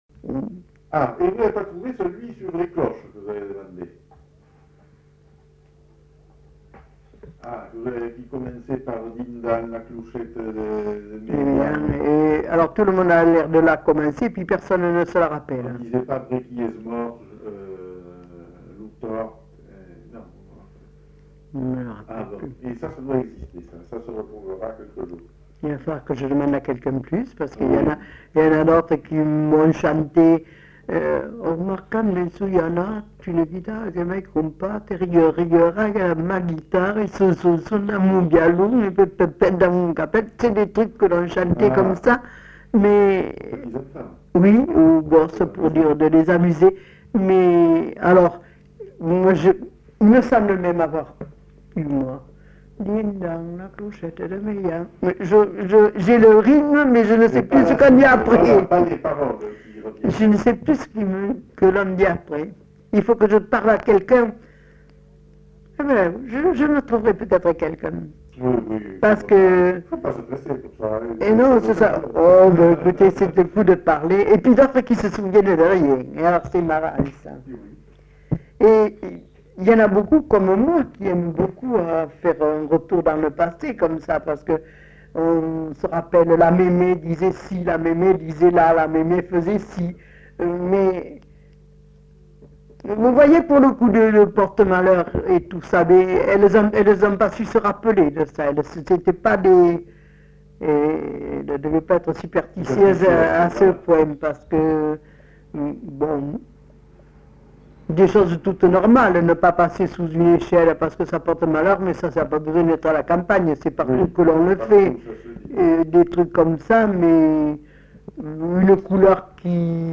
Aire culturelle : Bazadais
Lieu : Grignols
Genre : témoignage thématique